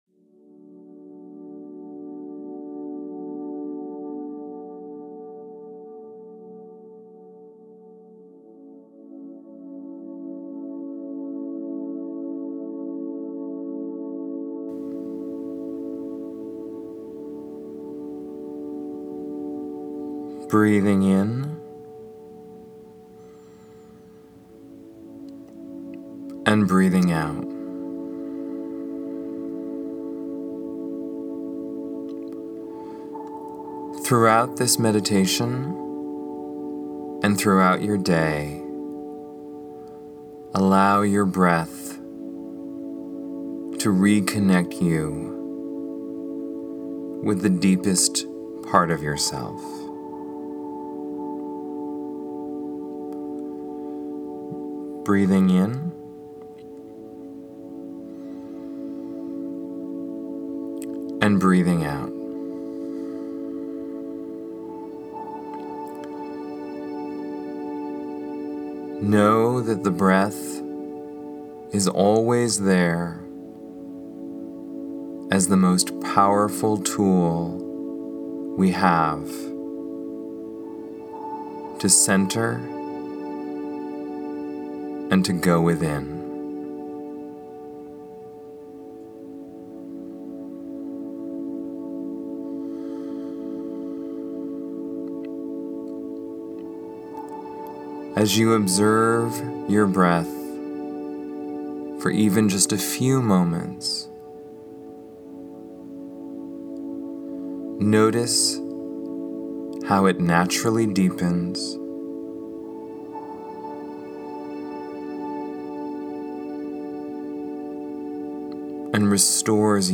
Connection Connection Meditation Download Download Prev Previous Self Acceptance Next The Ultimate Mobile Device Next Leave a Reply Cancel reply Your email address will not be published.